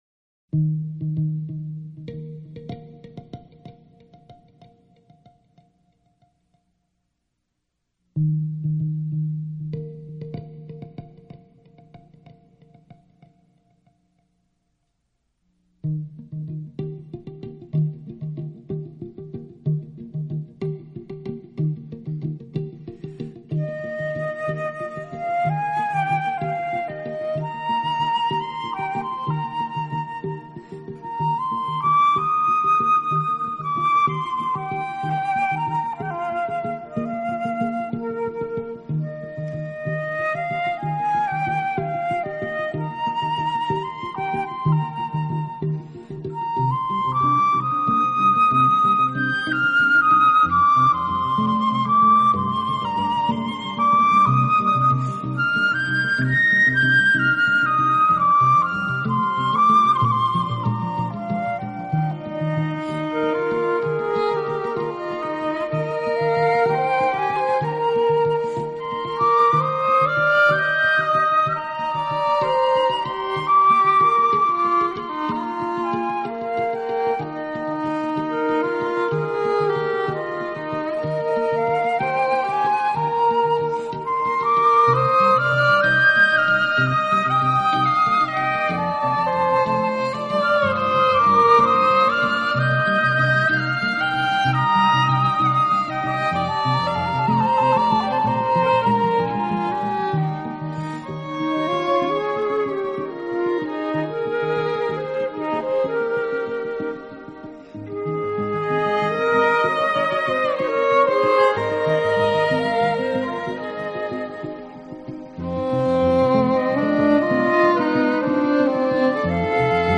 感受自然、清新、脱俗的新世纪音乐。
CD1 - Acoustic